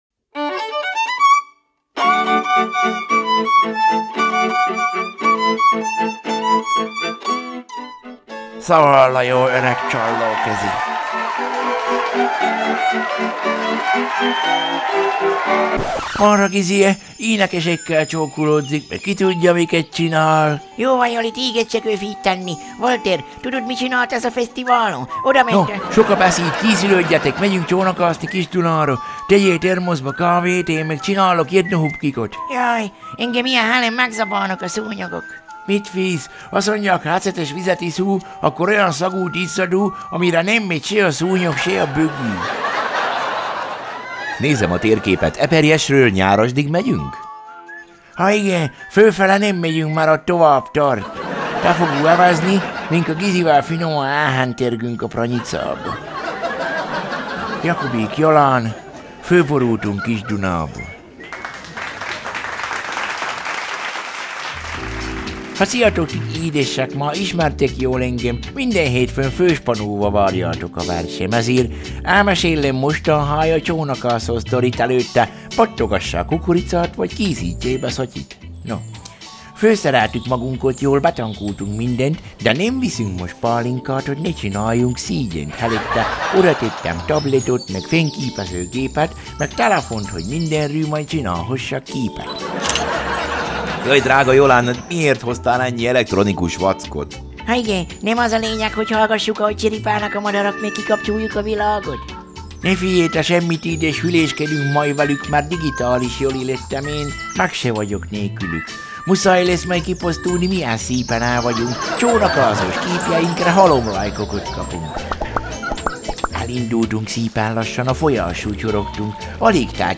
Pósfa Zenekar – Ó, én édes komámasszony
Tüskevár filmzene
Jackie Mittoo – Totally Together